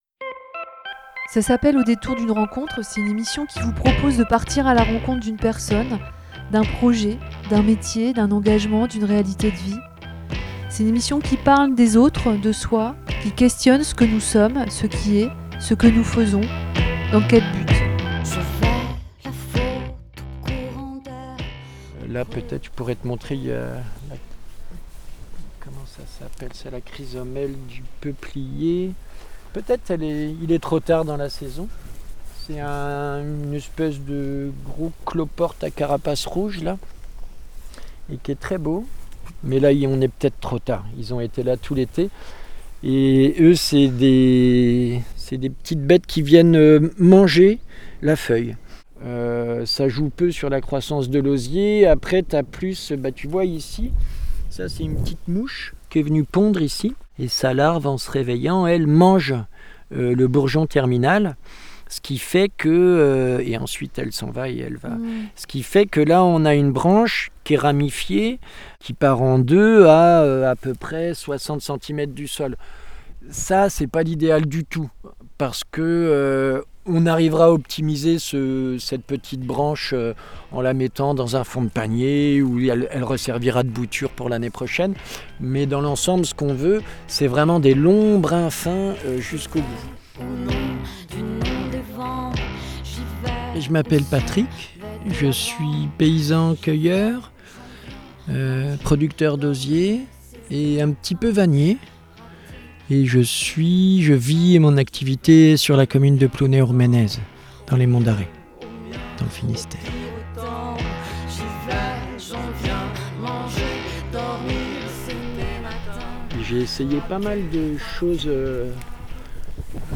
Nous passons d'abord un peu de temps dans l'Oseraie, pour écouter, observer, comprendre ce qu'est l'activité d'un producteur d'osier.